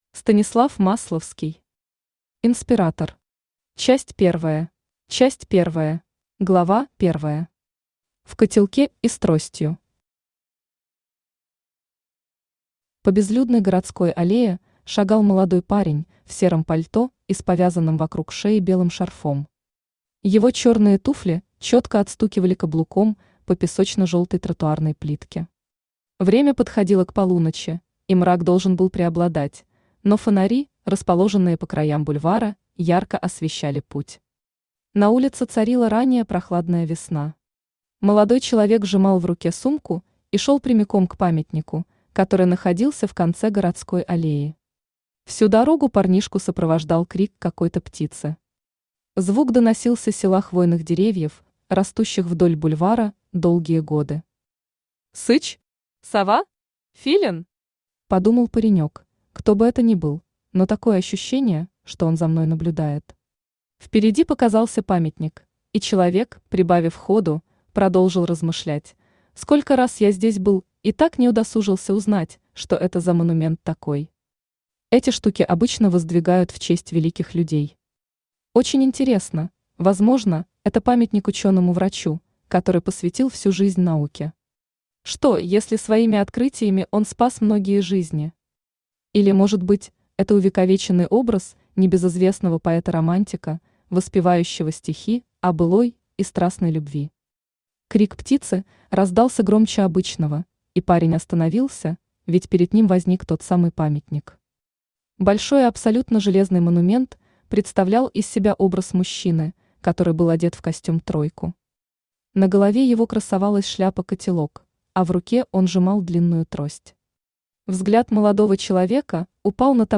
Аудиокнига Инспиратор. Часть первая | Библиотека аудиокниг
Часть первая Автор Станислав Масловский Читает аудиокнигу Авточтец ЛитРес.